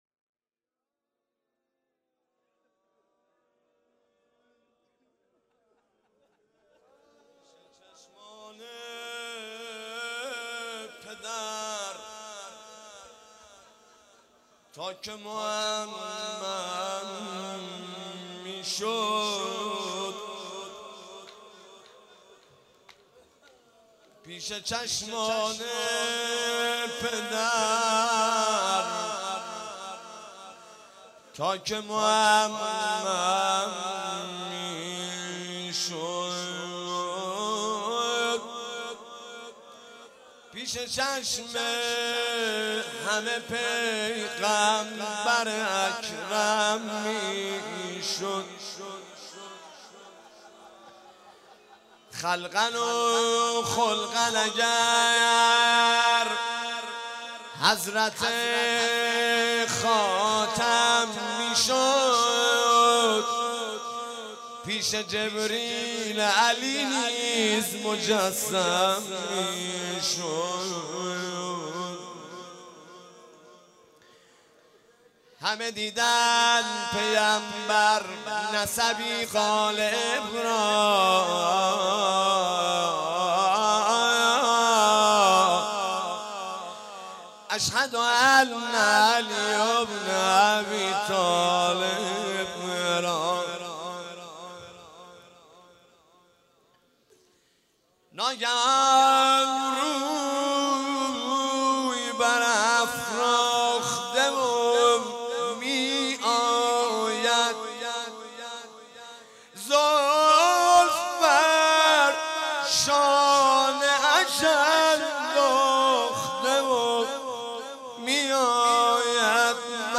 روضه حضرت علی اکبر